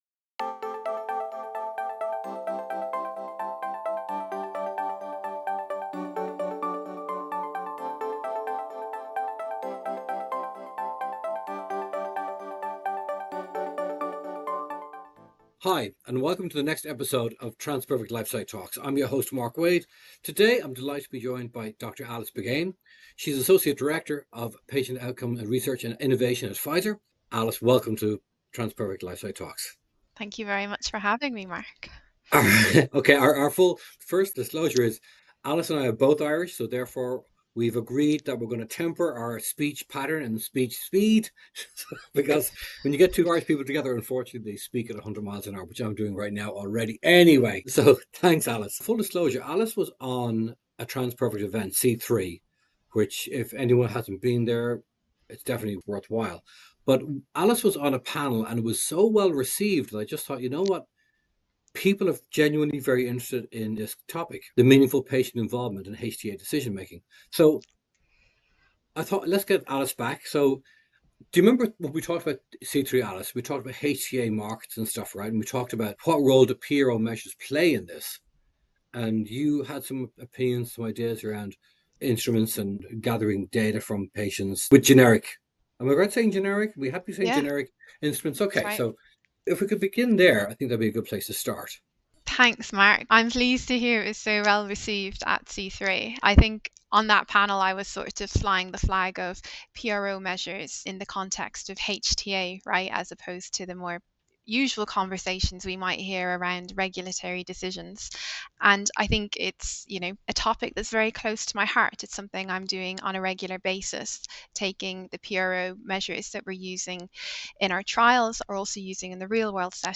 The interview covers their editorial titled, ‘Over a decade later and Addiction journal is still committed to publishing qualitative research’.